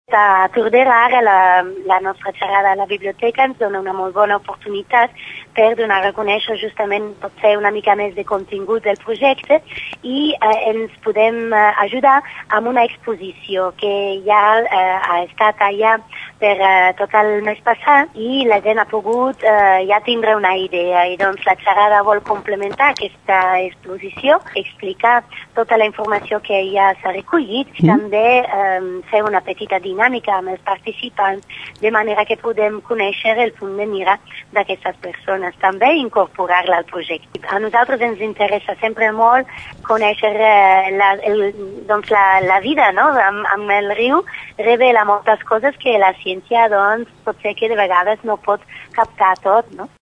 En declaracions a Ràdio Tordera